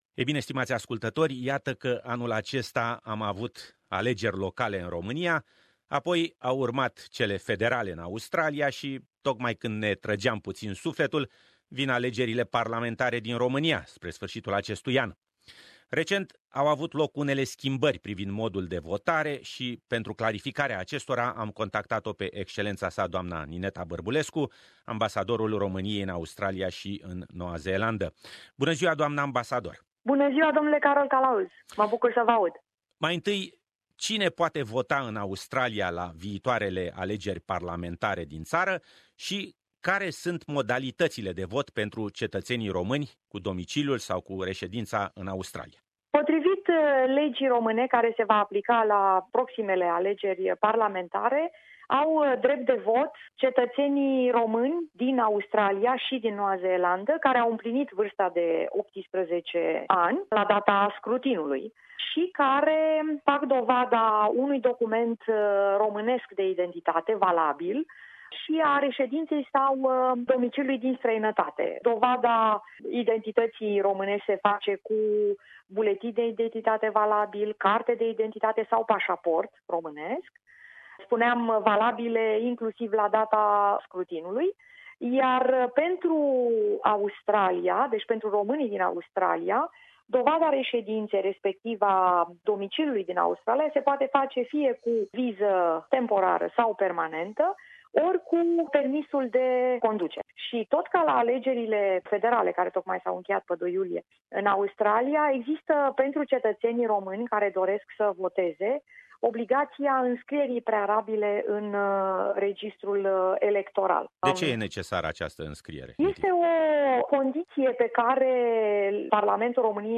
Interviu Ambasador Nineta Barbulescu - alegeri parlamentare